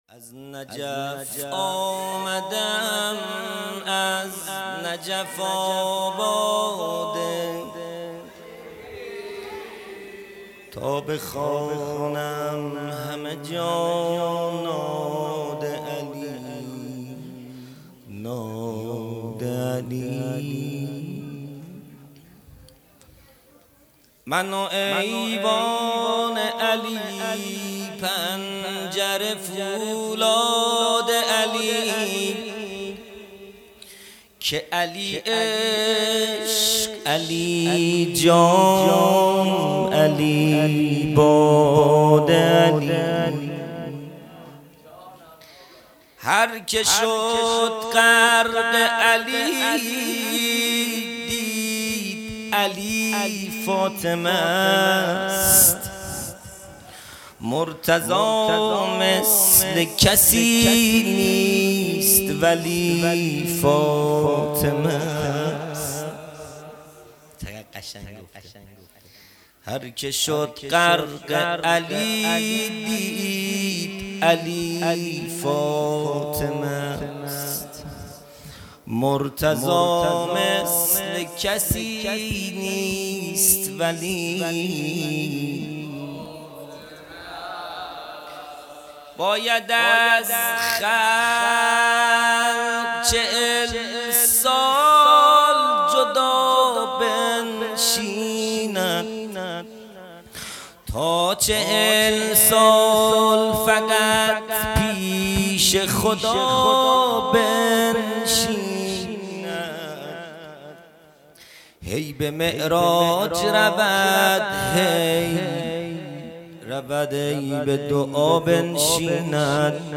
خیمه گاه - هیئت بچه های فاطمه (س) - مدح | از نجف آمده ام از نجف آباد علی | 30 دی 1400
جلسۀ هفتگی | ولادت حضرت زهرا(س)